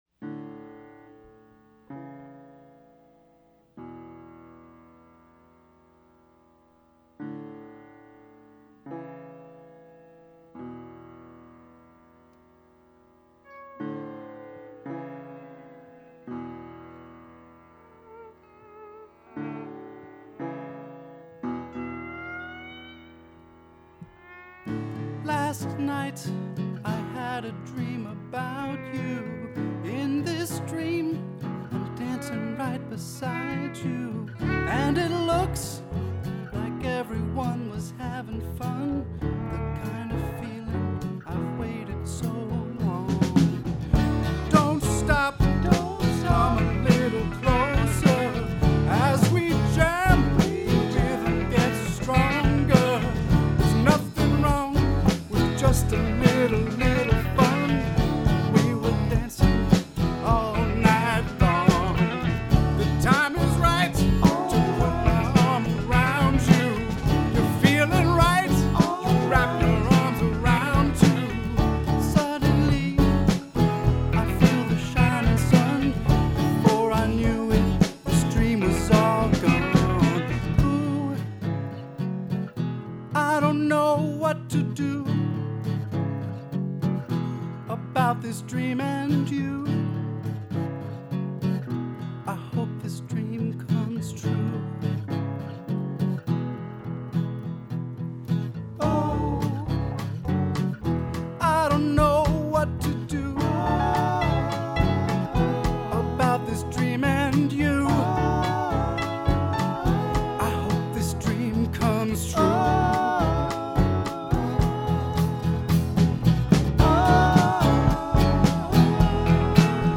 Then we went back in and recorded the overdubs.
I played bass and the electric guitars as well.
We all chimed in for the beautiful backing vocals.
It has a classic Rock feeling that feels so so right.